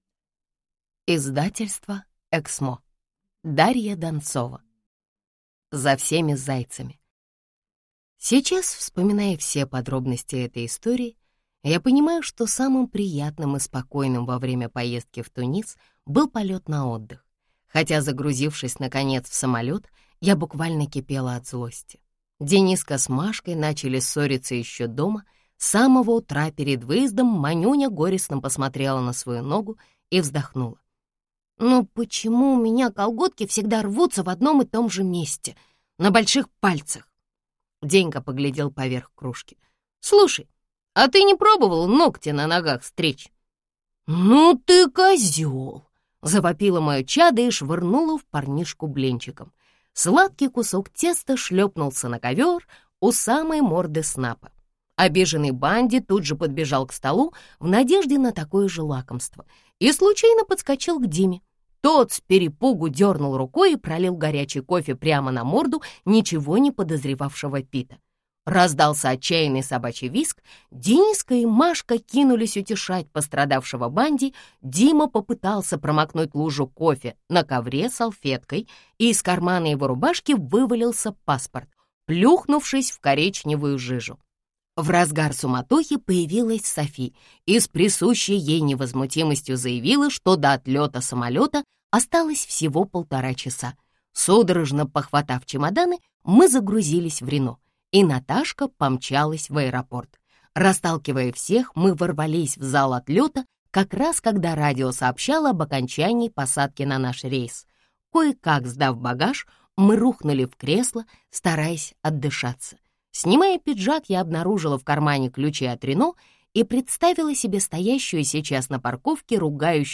Аудиокнига За всеми зайцами | Библиотека аудиокниг